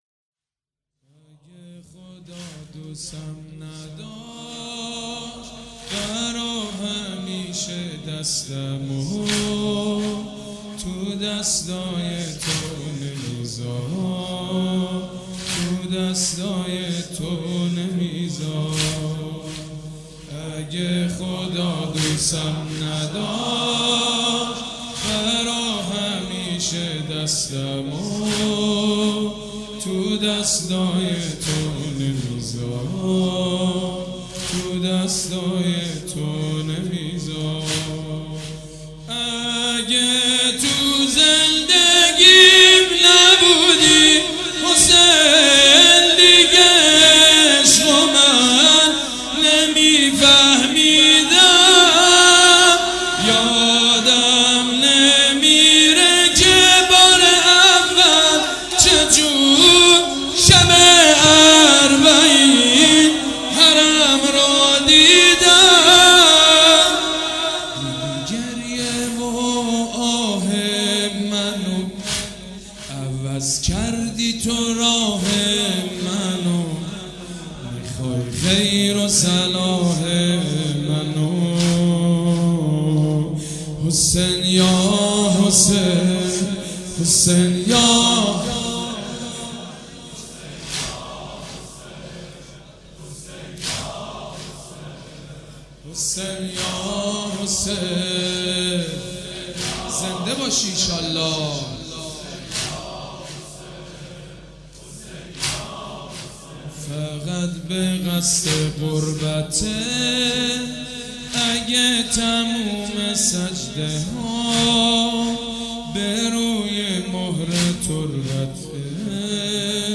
حاج سيد مجید بنی فاطمه
محرم 95